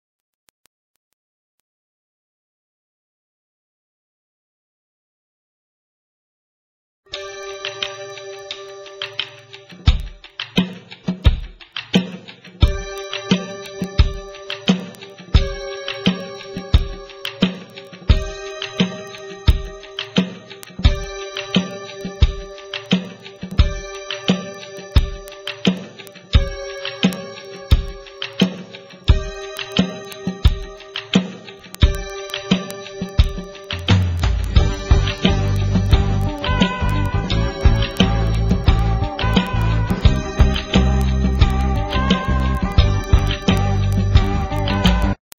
NOTE: Background Tracks 1 Thru 8